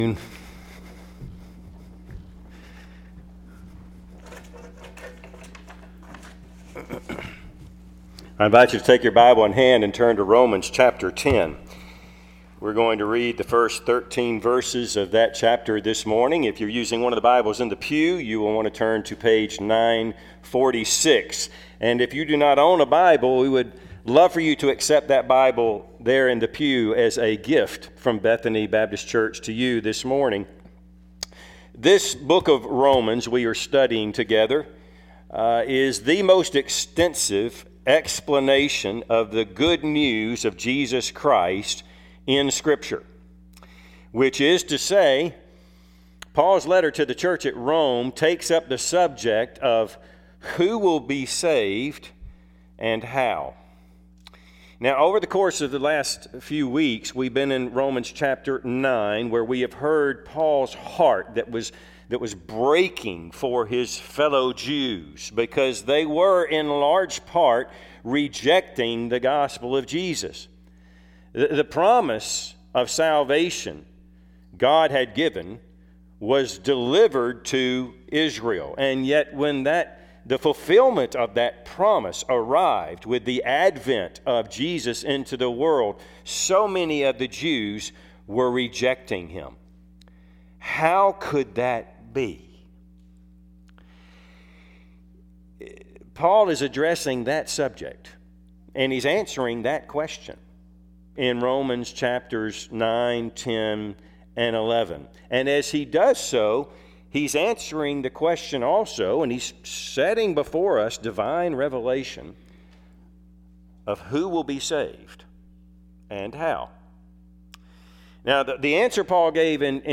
Passage: Romans 10:1-13 Service Type: Sunday AM